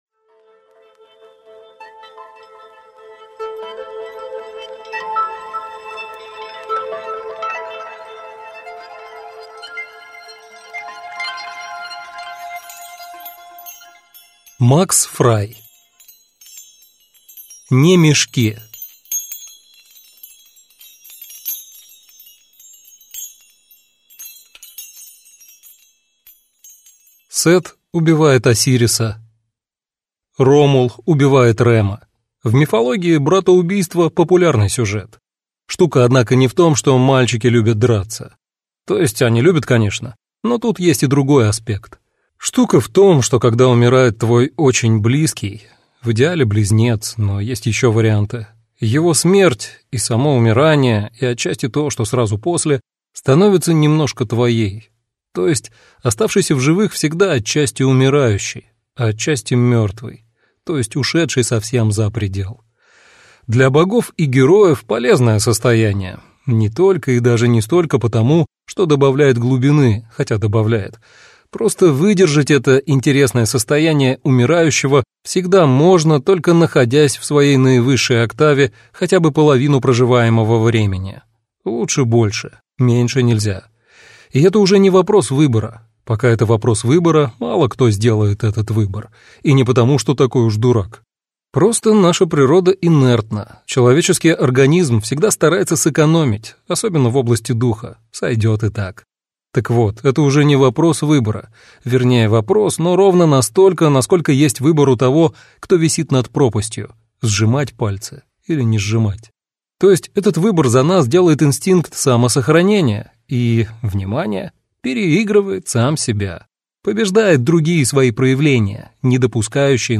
Аудиокнига Не мешки | Библиотека аудиокниг